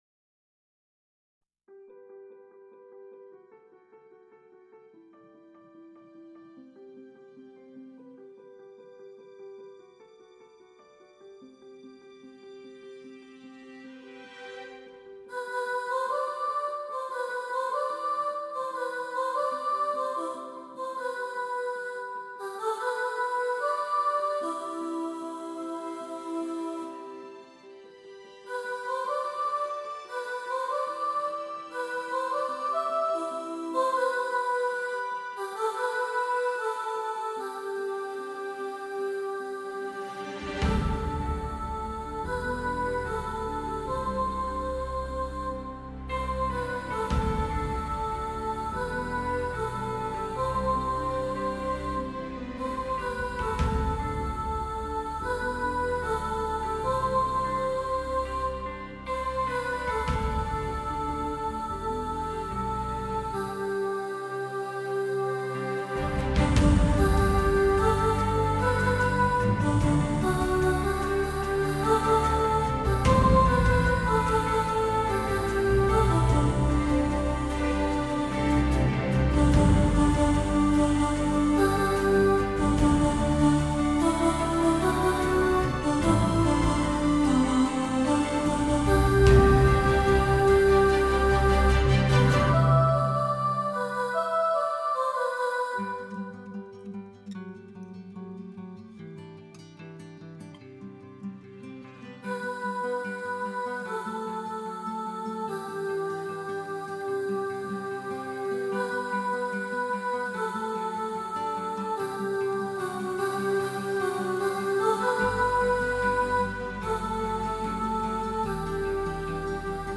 Million-Dreams-Soprano2.mp3